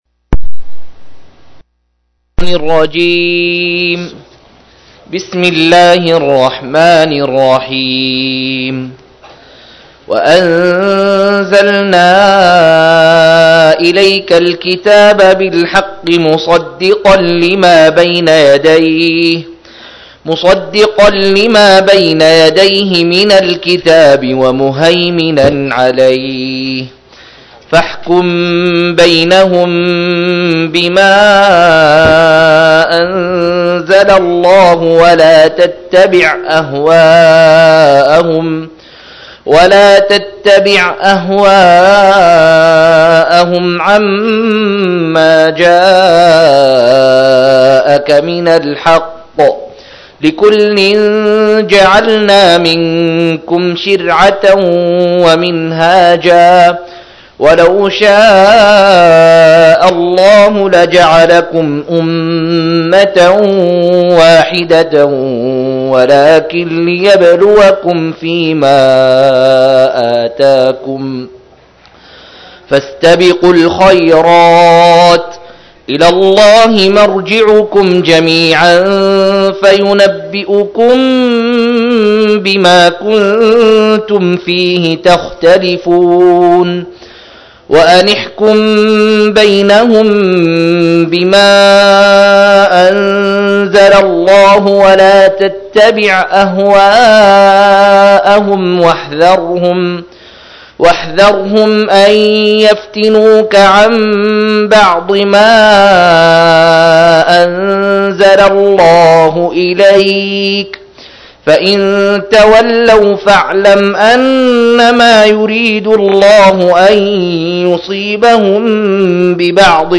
117- عمدة التفسير عن الحافظ ابن كثير رحمه الله للعلامة أحمد شاكر رحمه الله – قراءة وتعليق –